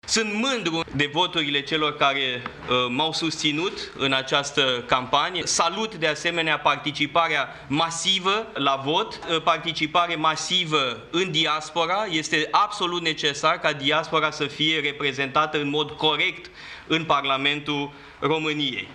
Rezultatul obţinut este bun, în contextul în care n-am avut dezbateri în campanie, am avut o criză de Guvern. Cred că e un rezultat foarte bun, de la care se poate clădi pentru viitor. Este evident că forţa care s-a exprimat în aceste alegeri va constitui un segment inconfundabil în reclădirea dreptei şi a centrului în România‘, a declarat Paleologu la sediul de campanie.